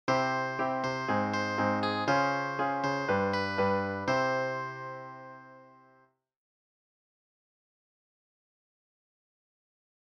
Let’s say that you’ve got a chord progression that is tonally quite fragile: C  Ab  Db  G  C. [LISTEN] It starts and finishes solidly in C, but takes a little detour through Ab major before ending up back in C major.
It might be better to try this: keep the innovative chord progression, and simplify the melody.
That simpler melody acts as a kind of “anchor” that gives the listener something predictable to help them with the much more creative chord progression.